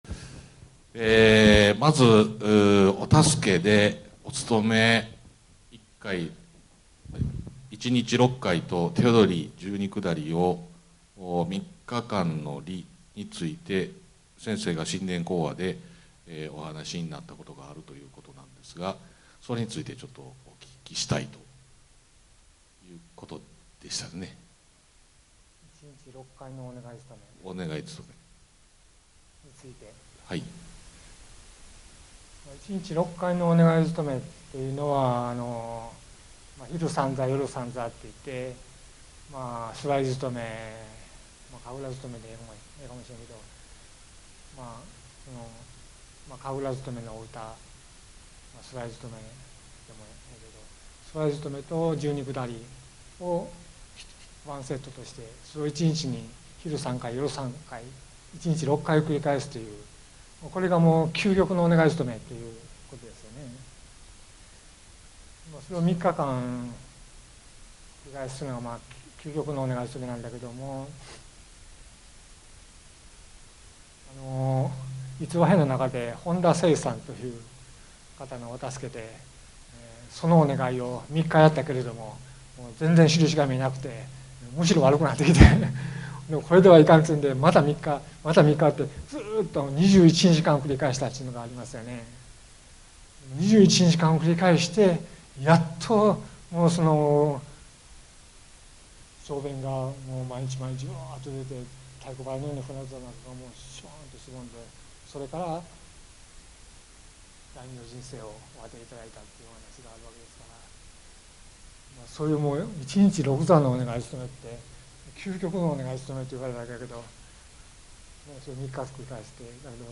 ビデオにより音量にばらつきがありますので、各自で調整をしてご視聴ください。
質疑応答.mp3